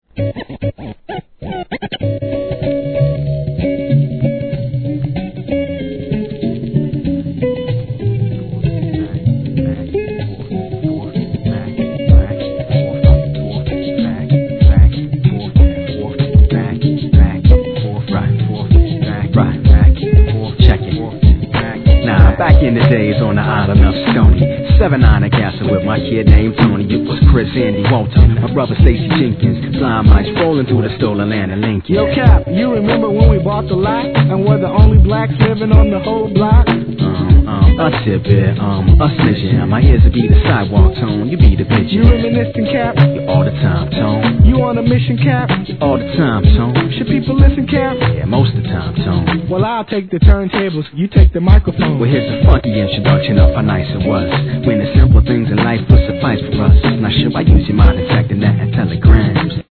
HIP HOP/R&B
心温まるメロウな響きの上物、優しい語り口のライミングが見事に融合したメロウHIP HOP!!